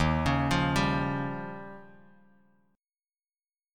Eb9 Chord
Listen to Eb9 strummed